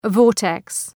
vortex.mp3